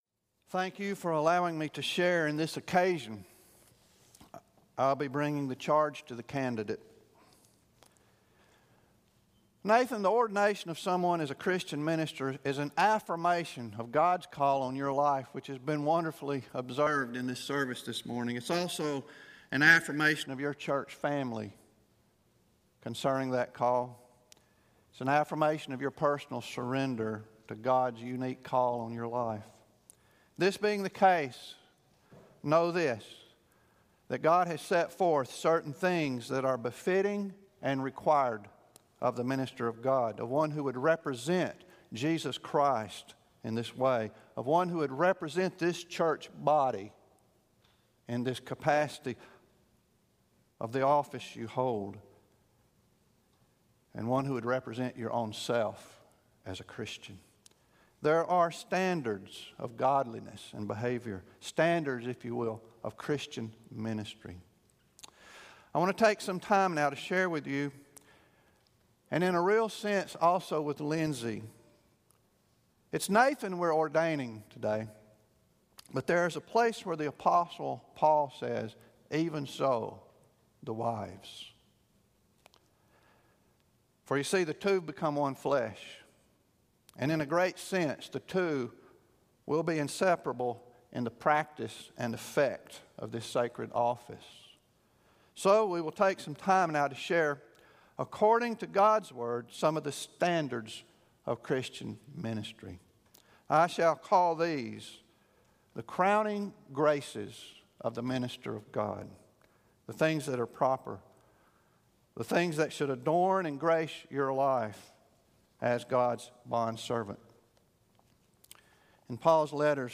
Ordination Service
September 25, 2016 Morning Worship Service